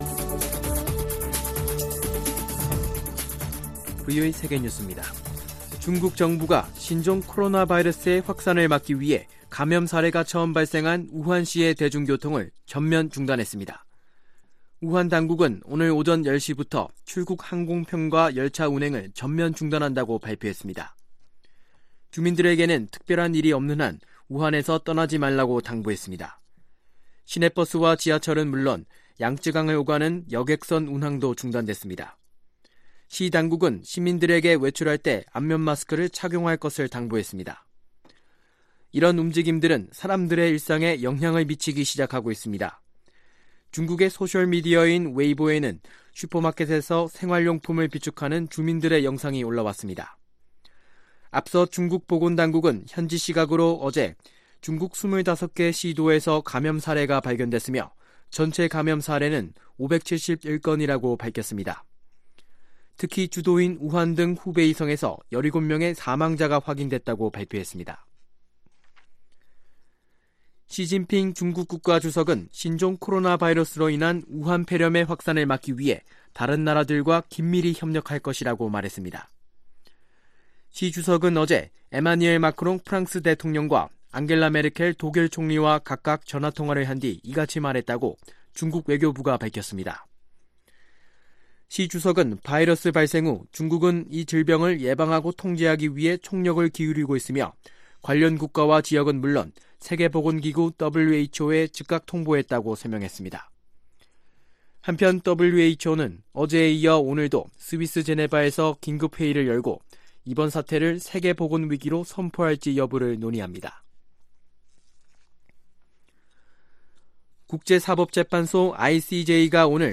VOA 한국어 간판 뉴스 프로그램 '뉴스 투데이', 2019년 1월 23일 2부 방송입니다. 미 국무부 고위 관리는 대북 외교가 느린 속도로 진행되고 있다며, 북한에 대한 압박을 지속할 것이라고 밝혔습니다. 유럽연합이 북한에 핵실험과 탄도미사일 발사 유예 등 신뢰 구축 조치를 취하고 비핵화 협상에 조속히 복귀할 것을 촉구했습니다.